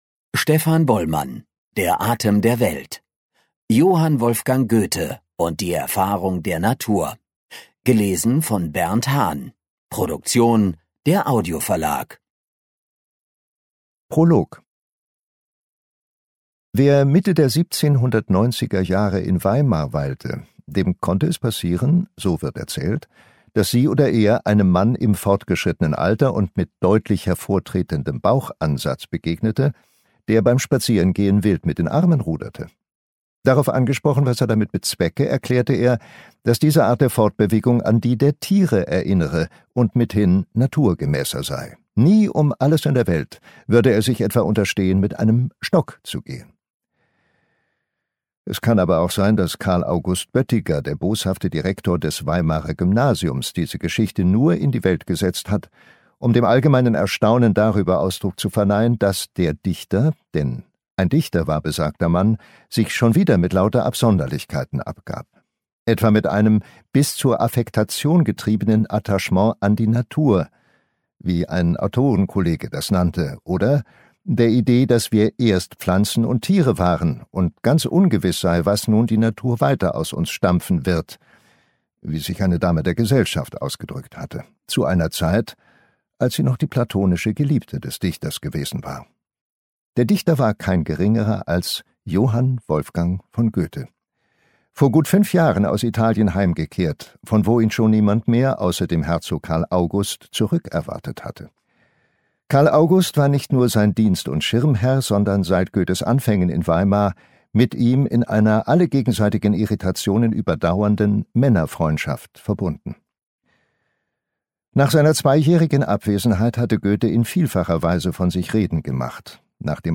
Hörbuch: Der Atem der Welt.
Der Atem der Welt. Johann Wolfgang Goethe und die Erfahrung der Natur Lesung